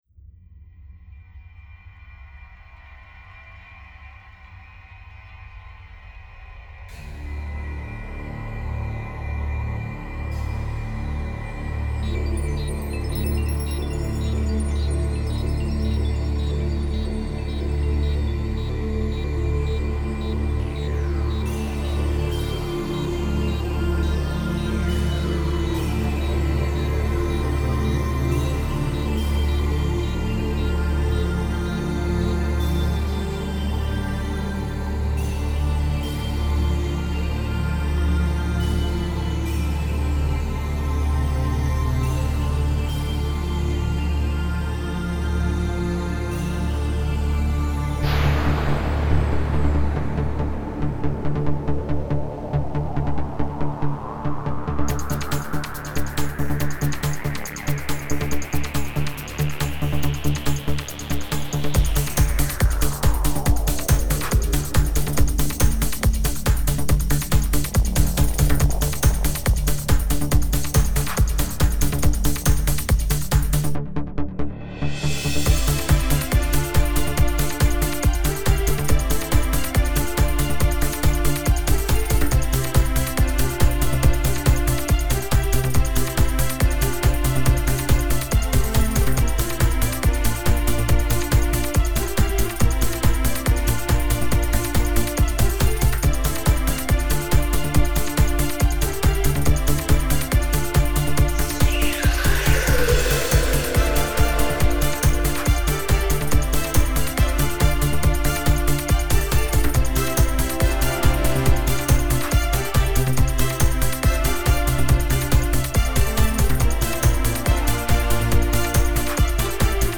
Emotion Alors, les 65 premières secondes cassent carrément la baraque je trouve, dès le départ je suis dedans, on est dans l'espace, quelques consonnances ethniques triturées, j'adore !!!! Puis, hum, la basse part, je sors de l'espace pour rentrer dans une boîte de nuit un peu trop flashy à mon goût, je caricature mais, je ne suis pas un grand amateur de ce style de rythme .... ça me casse un peu la baraque, surtout que je trouve que cela dure un peu longtemps avant de retomber sur une phase plus intime et plus sympa je trouve, bref le milieu me choque, je suis désolé. 2/5
Orchestration Un mélange savant d'éléctro, d'orchestre, de consonnances ethniques, j'accroche. de 55sec à 1min05, ca me rapelle un morceau de Bregovic qui est sur Silence of the Balkans, je suis super fan !